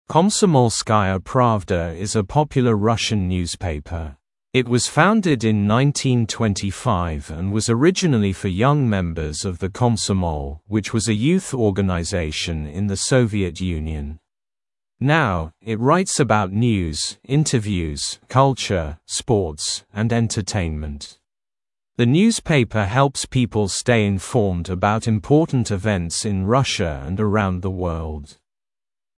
Произношение:
[Комсомольская Правда из э попьюлэ рашн ньюзпейпэ. Ит уоз фаундид ин найнтиин твенти файв энд уоз ориджинэли фо ёнг мембэз ов зэ Комсомол, уич уоз э юс оргэнайзейшн ин зэ Совиэт Юнион. Нау ит райтс эбаут ньюз, интевьюз, калчэр, спортс, энд энтэйнмэнт. Зэ ньюзпейпэ хелпс пипл стэй информд эбаут импотэнт ивентс ин Рашэ энд эраунд зэ уорлд].